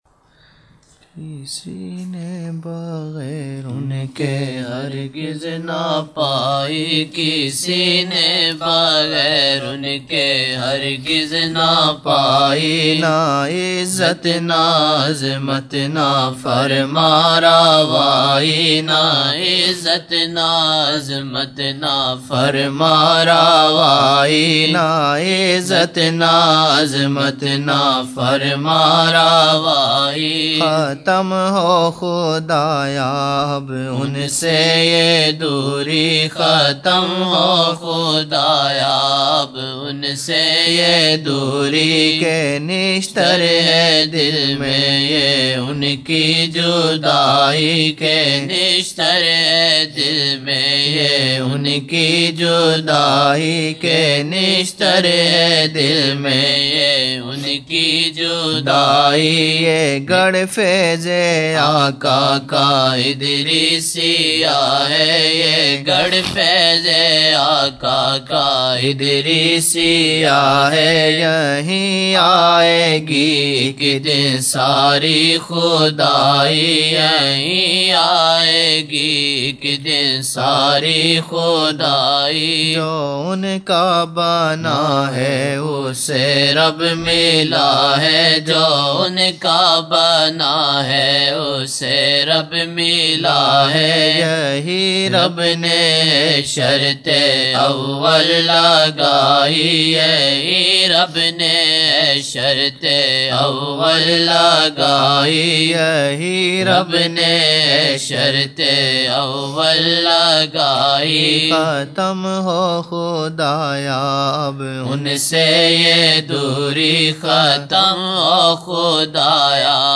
Naat Shareef